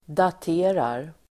Uttal: [dat'e:rar]